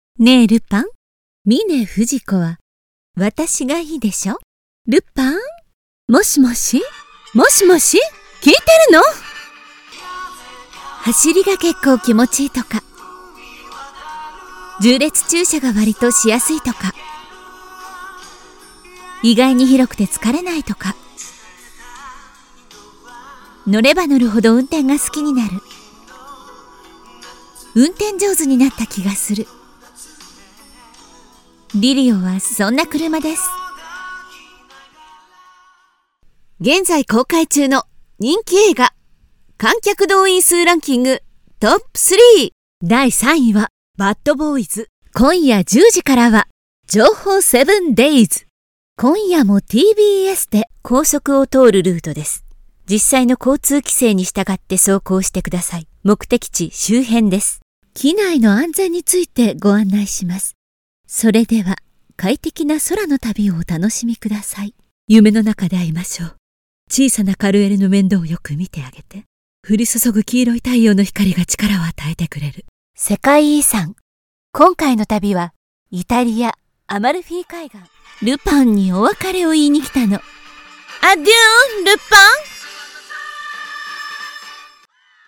Ideal for corporate narration, IVR, animation, and commercials, she delivers professional voice over services with broadcast-level clarity and speed.